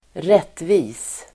Uttal: [²r'et:vi:s]